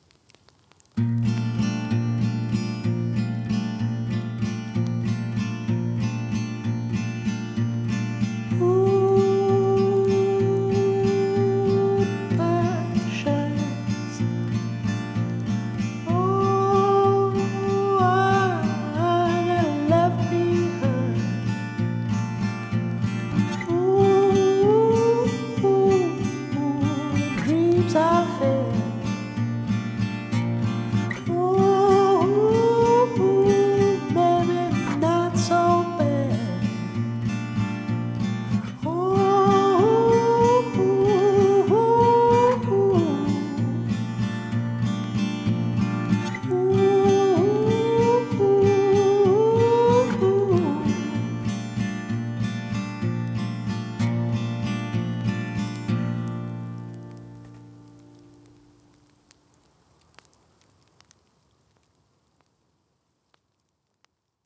Country › Folk